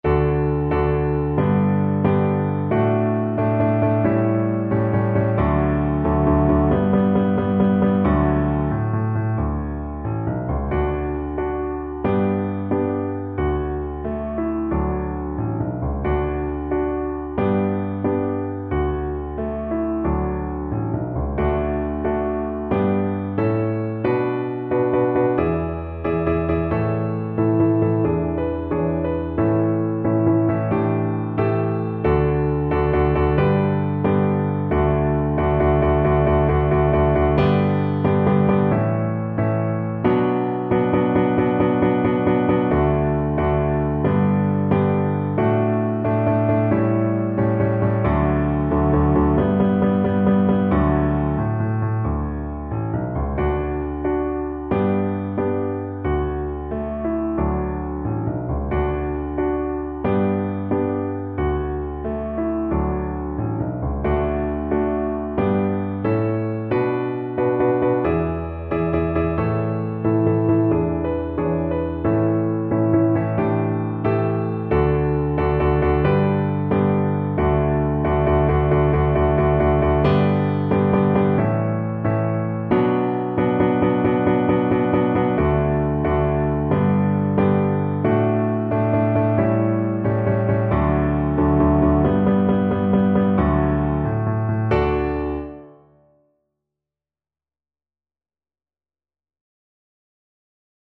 Traditional Trad. We Shall Overcome Viola version
Viola
D major (Sounding Pitch) (View more D major Music for Viola )
Moderately slow with determination (=90)
4/4 (View more 4/4 Music)